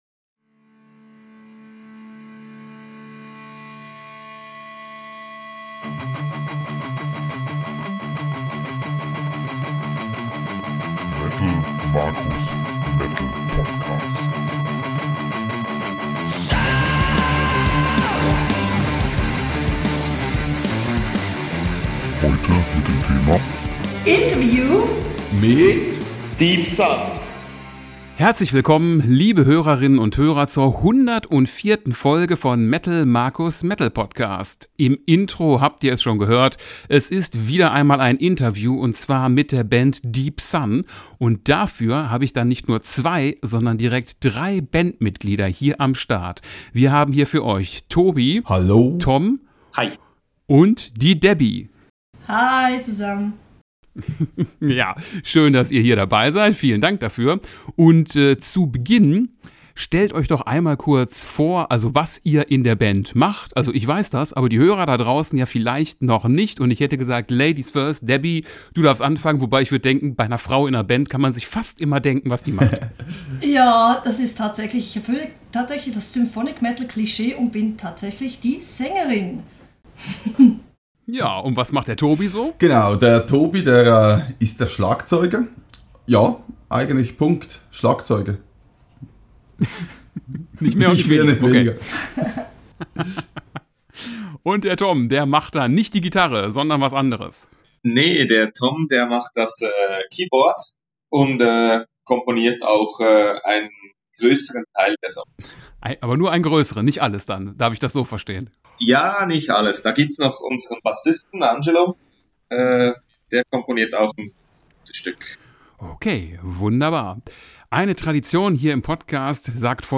#104 - Interview mit Deep Sun